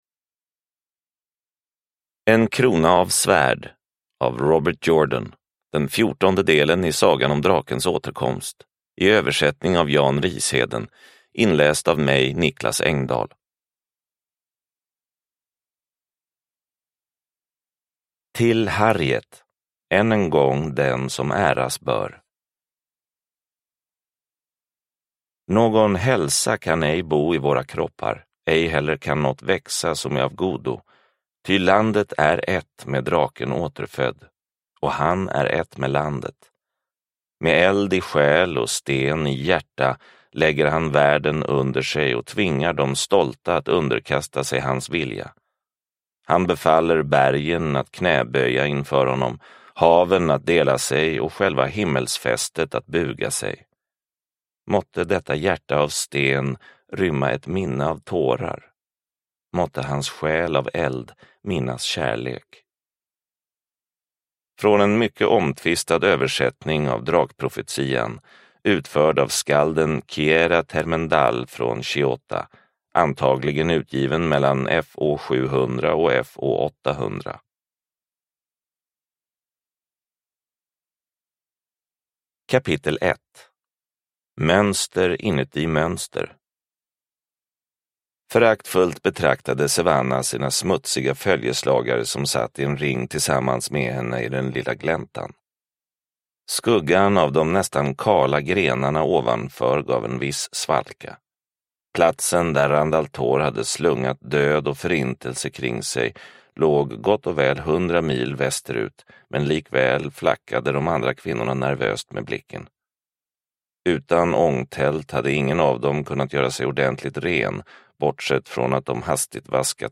En krona av svärd – Ljudbok – Laddas ner